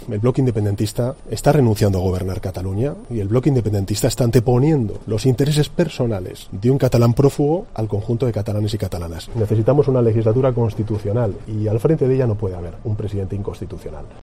Sánchez, que ha hecho estas declaraciones en los pasillos del Congreso antes de reunirse con la dirección de su grupo parlamentario, ha acusado al bloque independentista de estar "renunciando a gobernar Cataluña, anteponiendo los intereses personales de un catalán prófugo al interés general del conjunto de los catalanes".